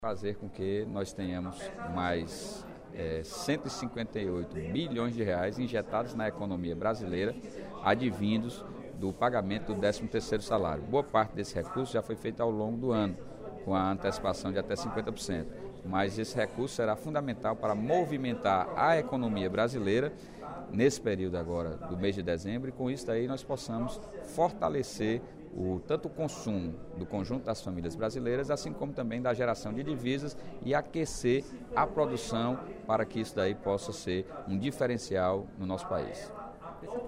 Durante o primeiro expediente da sessão plenária desta quarta-feira (05/11), o deputado Sérgio Aguiar (Pros) comemorou o resultado do valor relativo ao 13º salário a ser pago este ano pelas empresas públicas e privadas, pois alcançará R$ 158 bilhões, montante superior em 10,1% aos R$ 143 bilhões do ano passado.